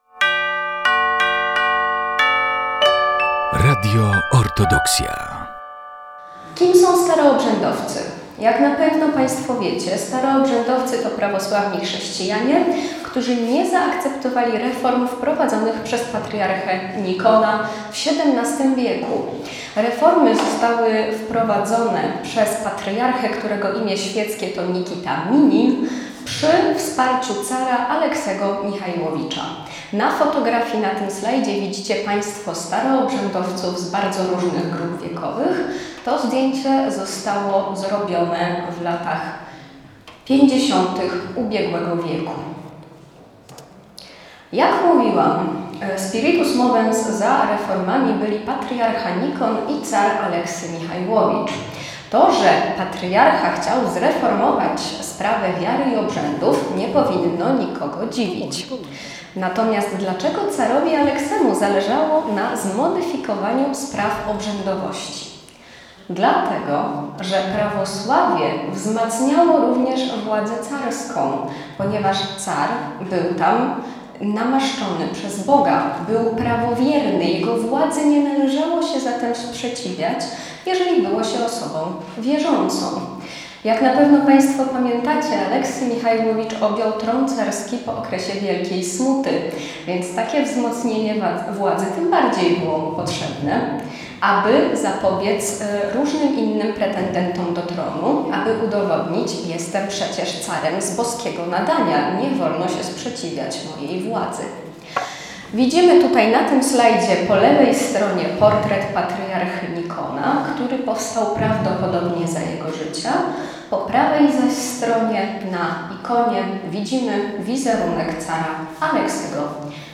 27 stycznia 2025 roku roku w Centrum Kultury Prawosławnej w Białymstoku odbył się kolejny wykład w ramach Wszechnicy Kultury Prawosławnej.